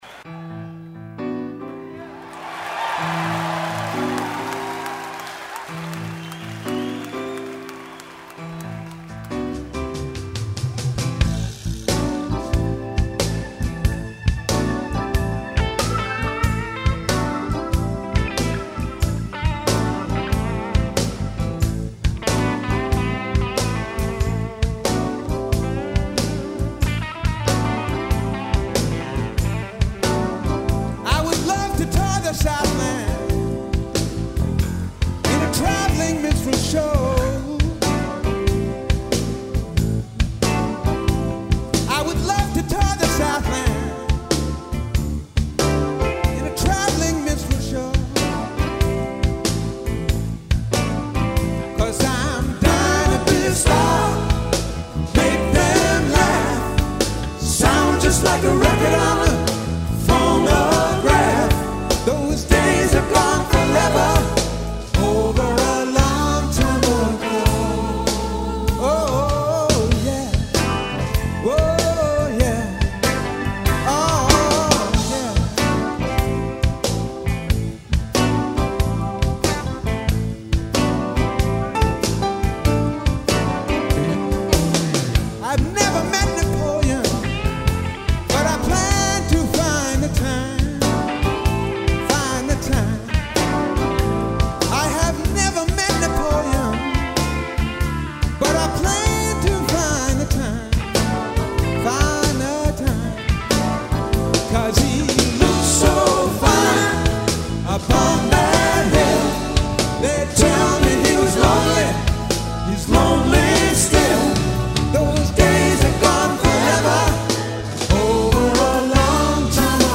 note perfect live version